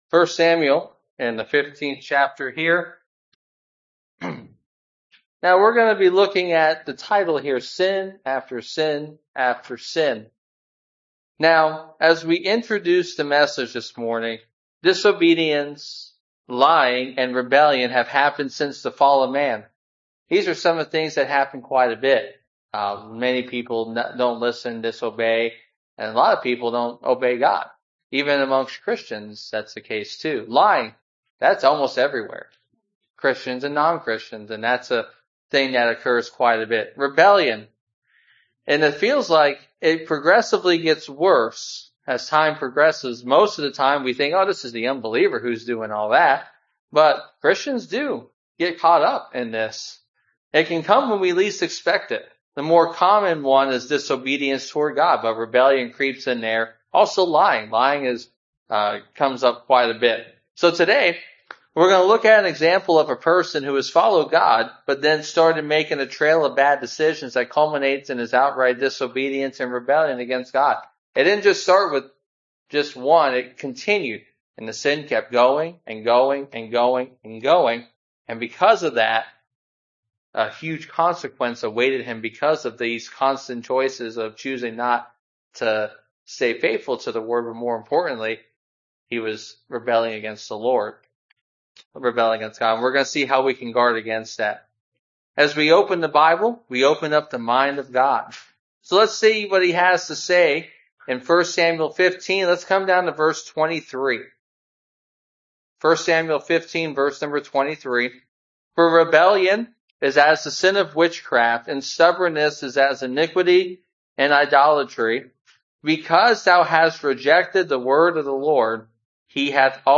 Exposition of Samuel Passage: 1 Samuel 15 Service Type: Sunday Morning (voice only) Download Files Bulletin « Why Jesus?